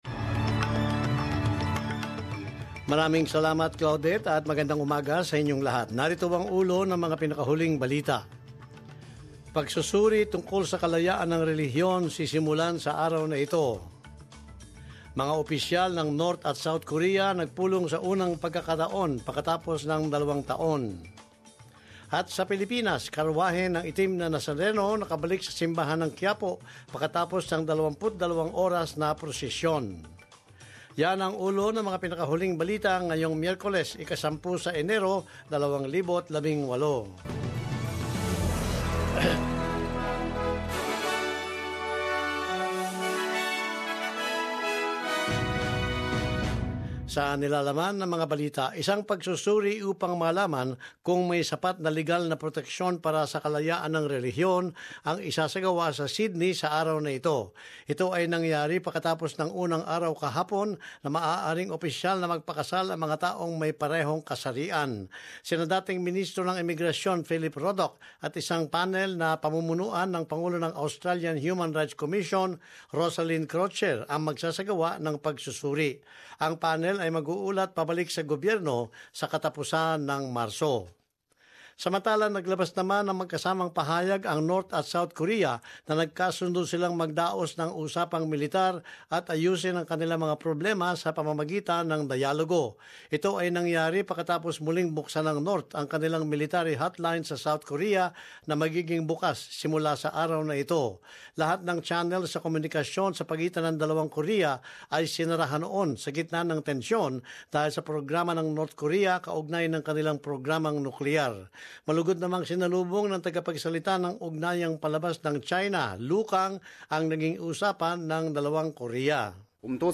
News Bulletin 10 Jan